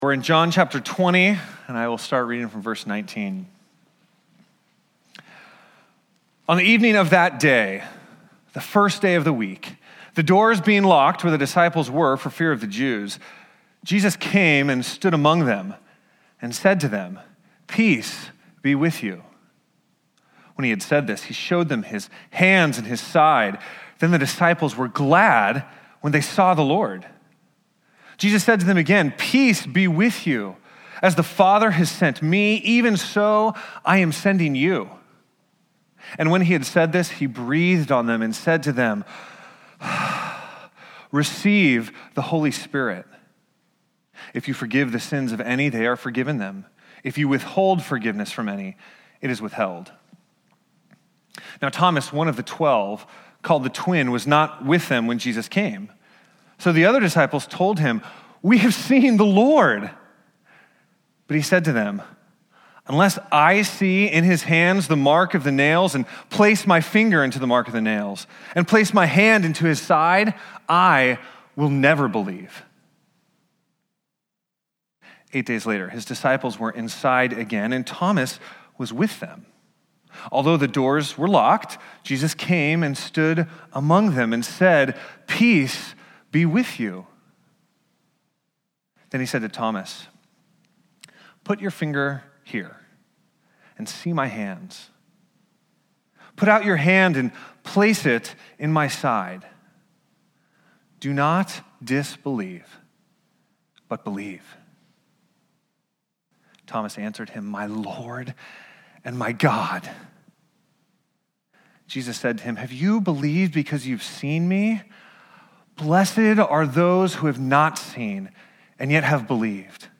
Book of John Passage: John 20: 19-31 Service Type: Sunday Topics